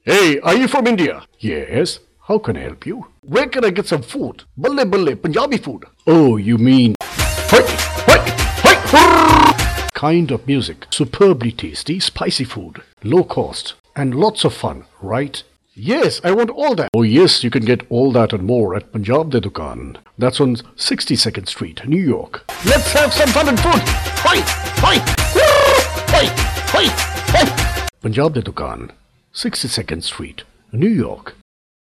indisches engl.
Sprechprobe: Werbung (Muttersprache):